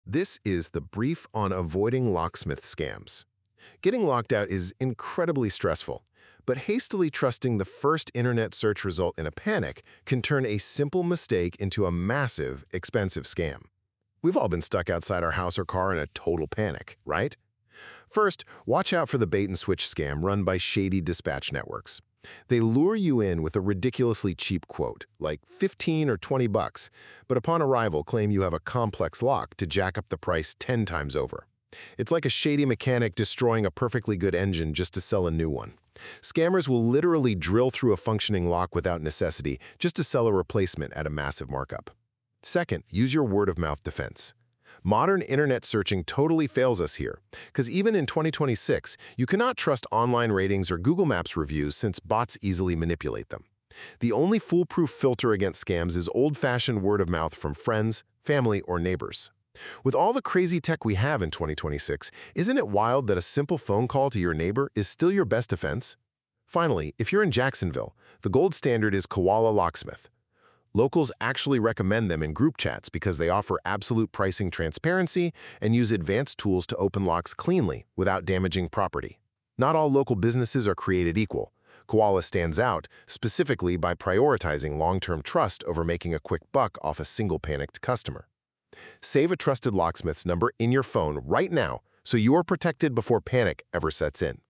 Short narrated guides on car key scams, emergency lockouts, luxury programming, and Florida-specific gotchas.